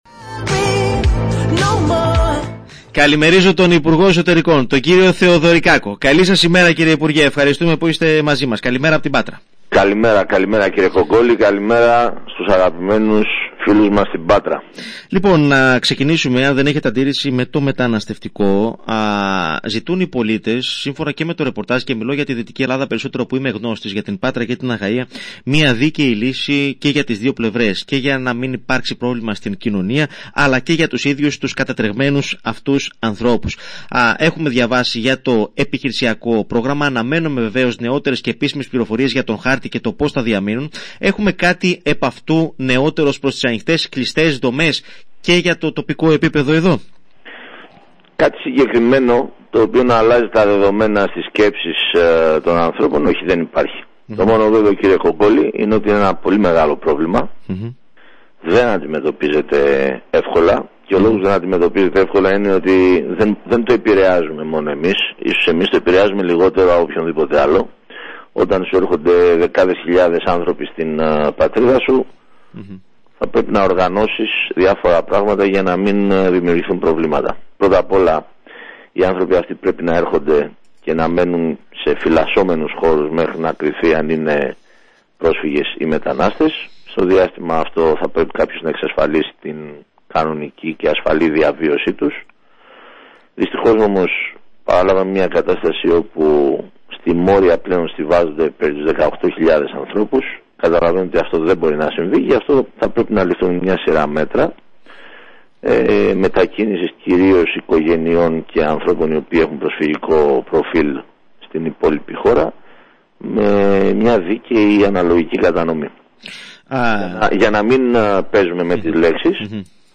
Συνέντευξη του Υπουργού Εσωτερικών Τάκη Θεοδωρικάκου στον ρ/σ Max Fm (Ηχητικό)